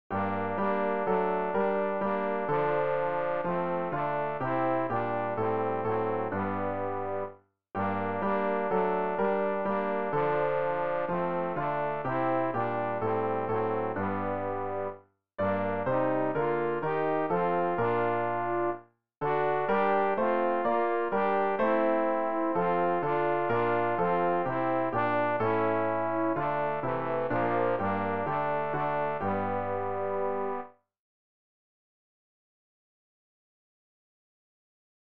tenor-rg-364-mit-ernst-o-menschenkinder.mp3